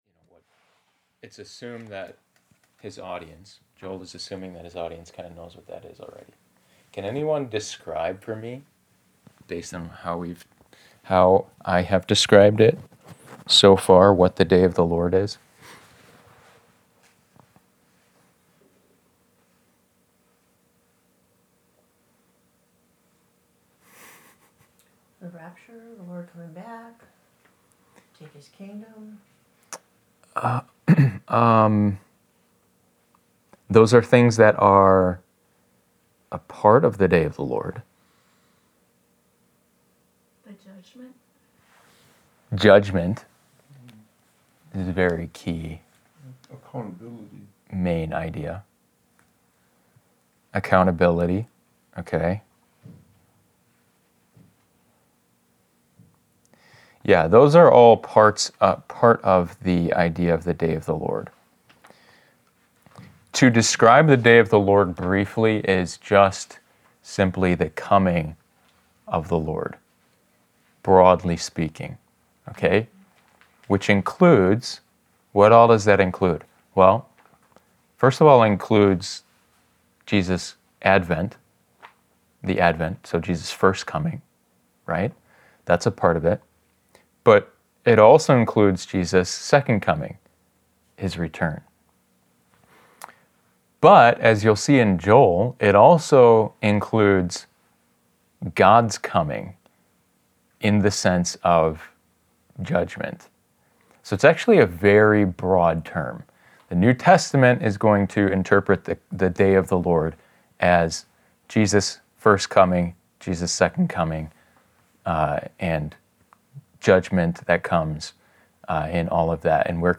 Joel —Wednesday Bible Lesson— Passage
Wednesday night Bible lessons are a bit more informal.